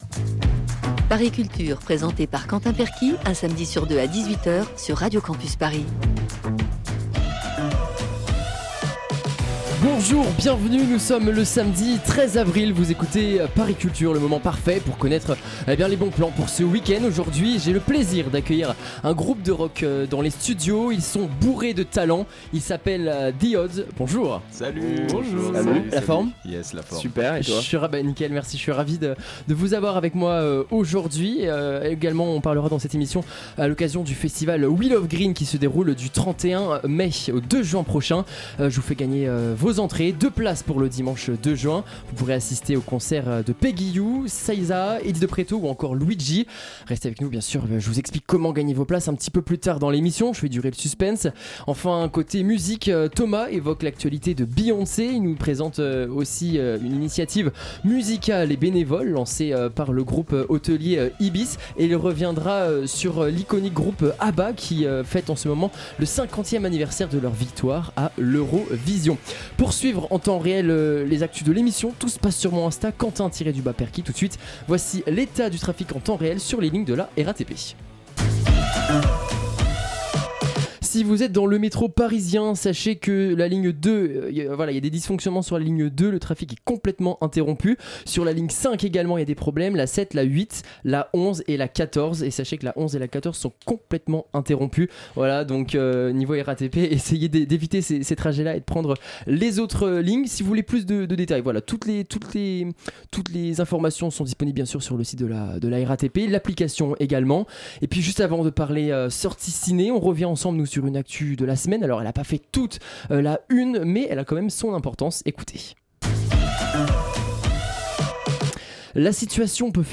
Les membres du groupe de rock The Odds sont avec nous dans La Rencontre (18min53).